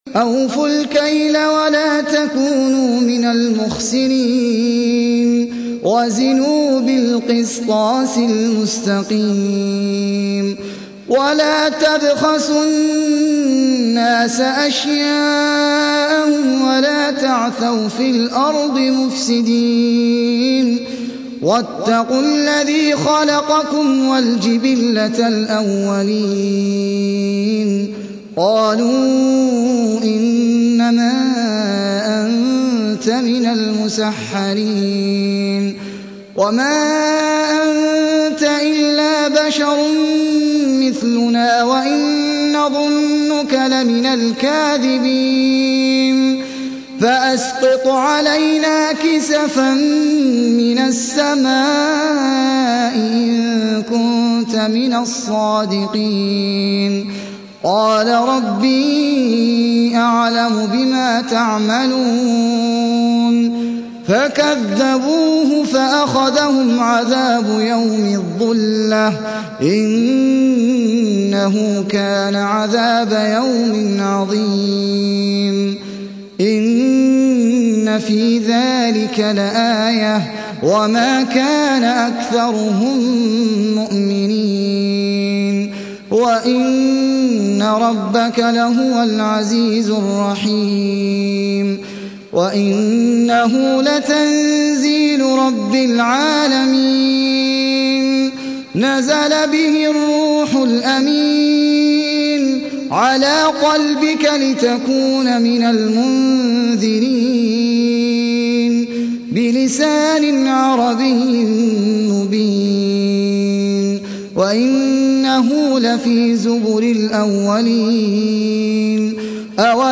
المكتبة الصوتية -> القرآن الكريم -> الشيخ أحمد العجمي